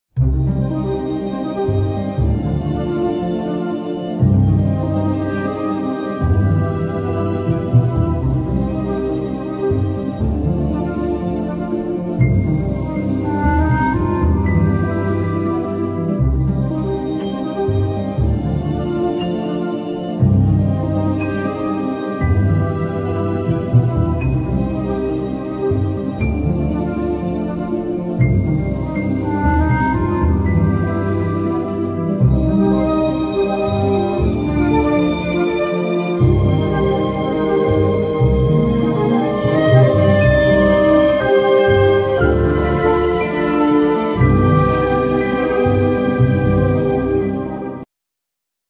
Violin
Viola
Cello
Flute
Clarinet
Harp
Drums
Accordeon
Guitars,Percussions